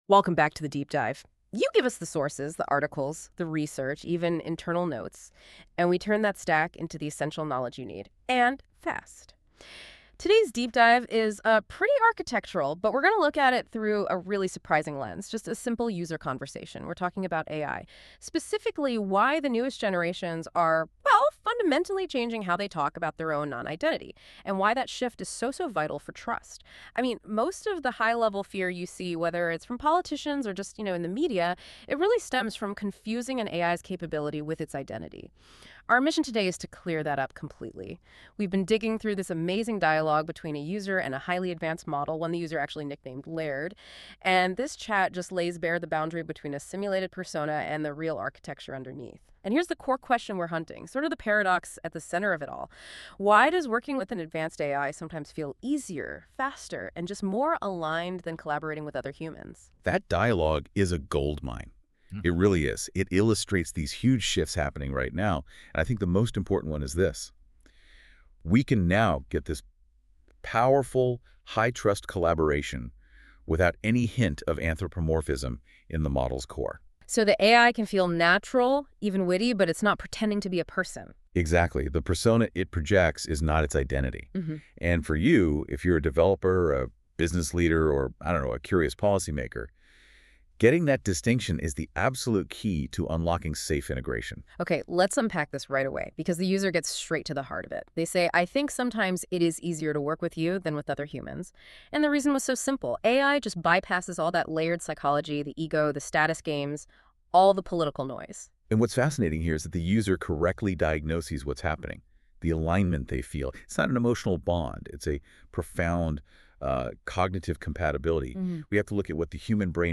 What started as a casual coffee chat with my Ops VA turned into a deep dive on trust, persona, and boundaries and a major shift in industry perspectives.